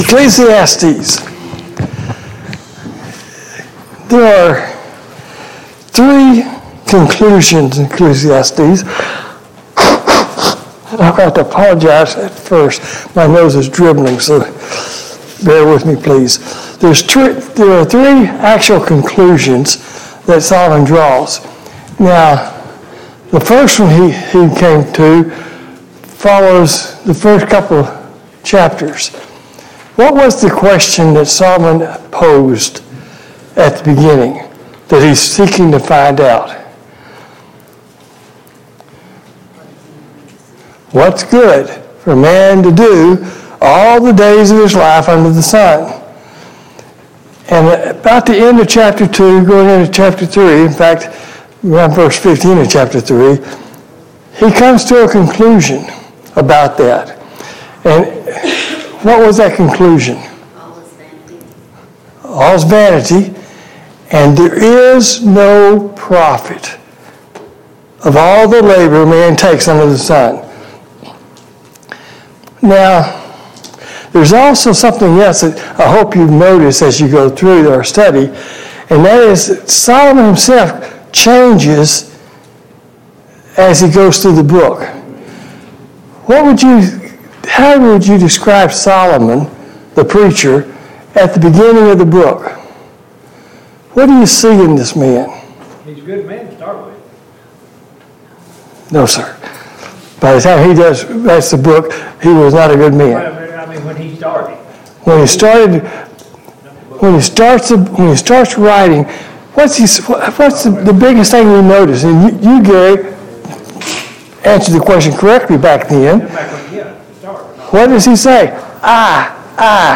A Study of Ecclesiastes Service Type: Sunday Morning Bible Class « 40.